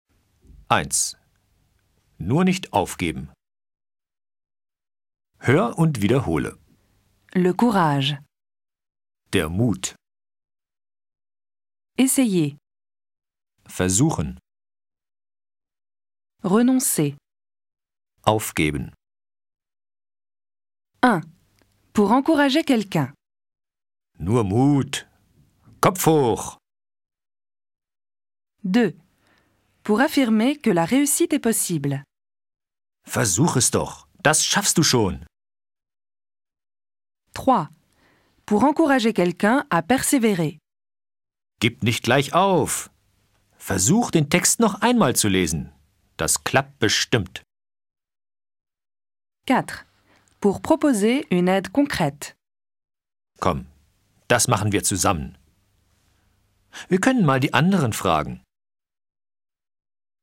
fichier son pour s'entrainer à répéter les expressions.